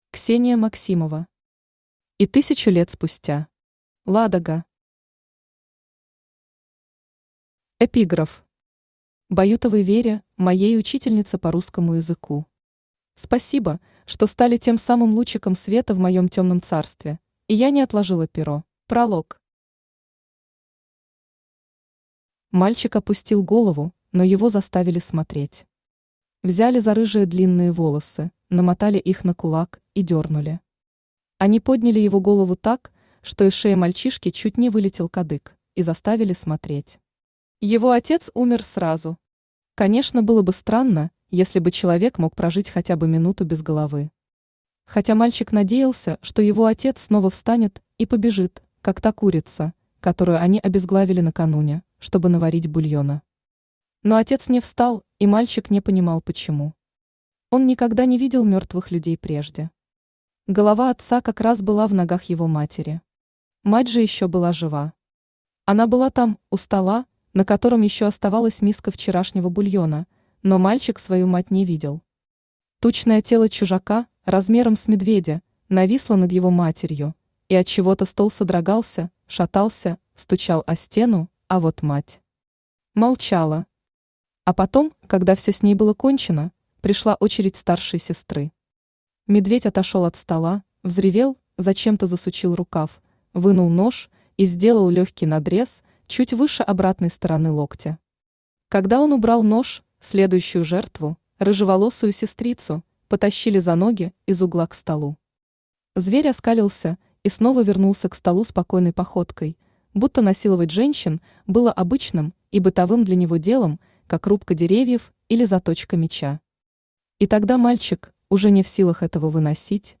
Аудиокнига И тысячу лет спустя.
Ладога Автор Ксения Максимова Читает аудиокнигу Авточтец ЛитРес.